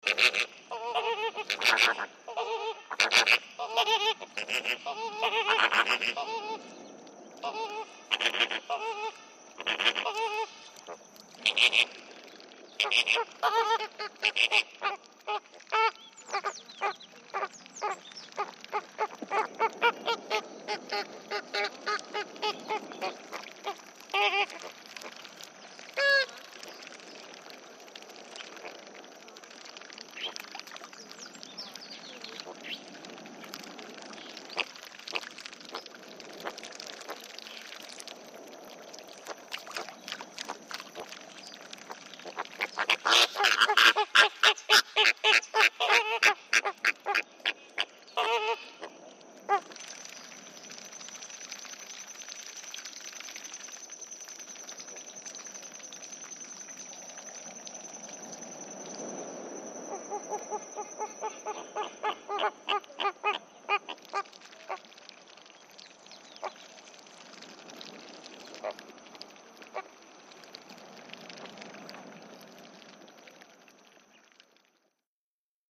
Flamingoes chattering and feeding, Galapagos Islands.